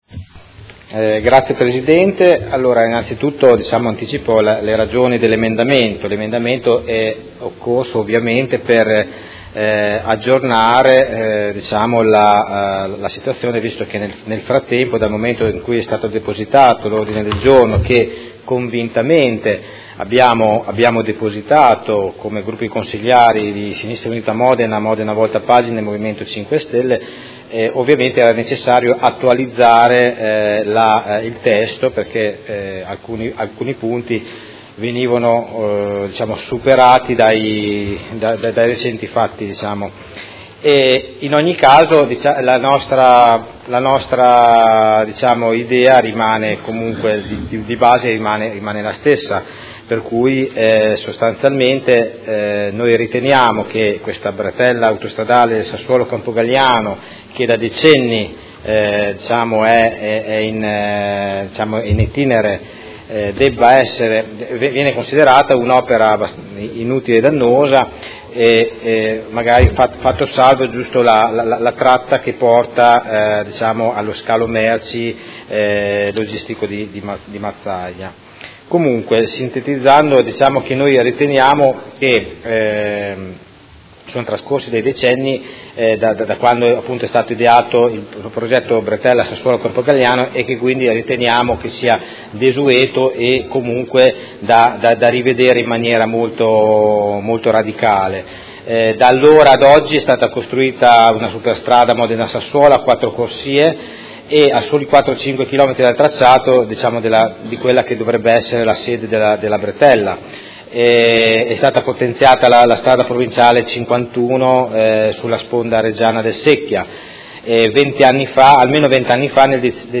Seduta del 28/03/2019. Illustra Ordine del Giorno presentato dai Consiglieri Stella (SUM), dal Consigliere Chincarini (Modena Volta Pagina) e dal Consigliere Bussetti (M5S) avente per oggetto: Bretella autostradale Sassuolo- Campogalliano: opera inutile e dannosa per il nostro ambiente: finanziare la manutenzione della viabilità esistente e sviluppare il potenziamento e la qualificazione delle tratte ferroviarie Sassuolo- Modena e Sassuolo-Reggio, ed emendamento